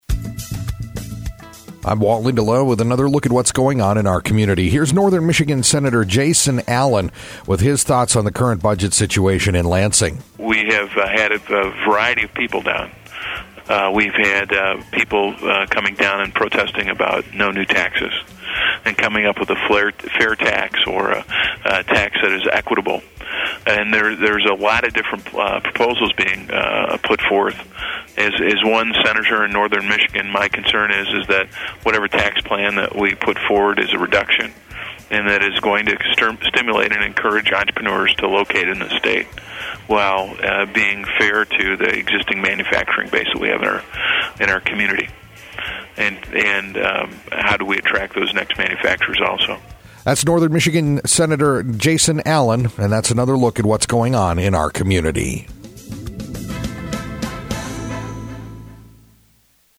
INTERVIEW: Jason Allen, State Senator